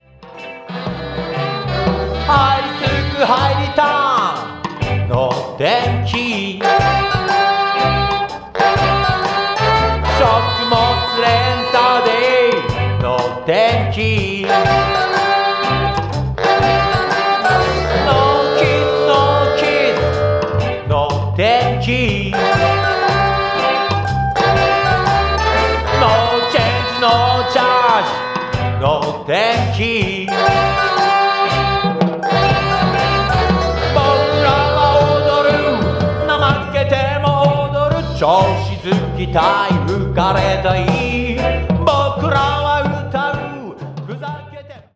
ジャンル：ロック
今度のアルバムも底ぬけに濃い。
ある時はファンク、フォークかもしれないが、でもアヴァンギャルド。